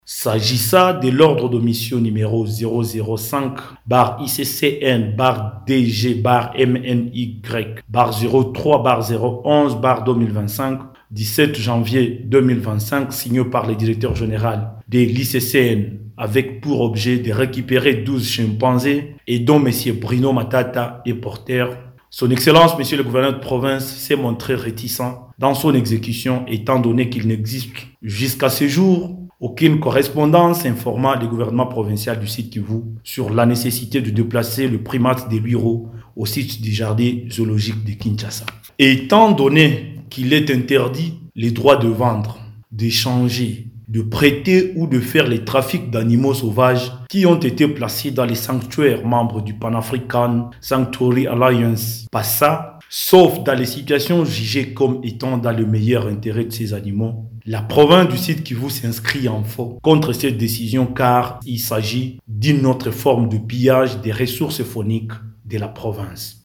Pour le Gouvernement provincial du Sud-Kivu, il s’agit d’une autre forme de pillage des ressources fauniques de la Province, explique Me Didier Kabi, ministre de l’environnement et porte-parole du gouvernement provincial du Sud-Kivu.